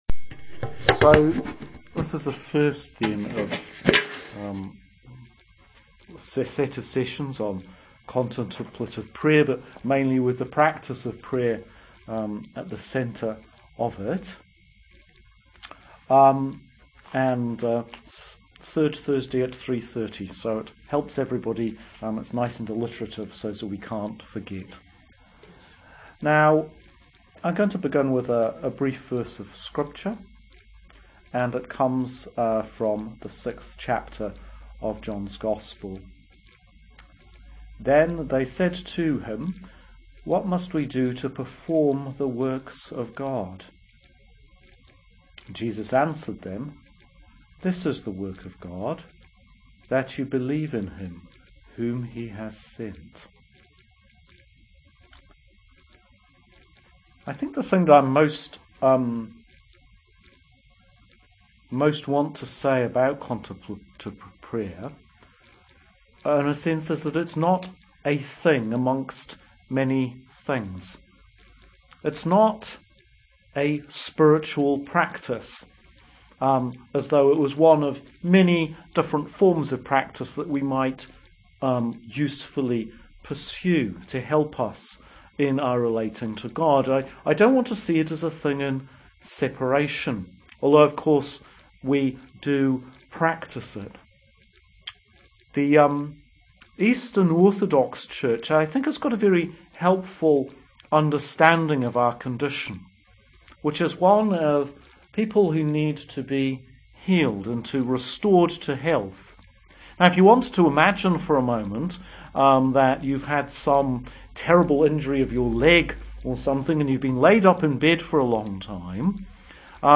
prayer-i.mp3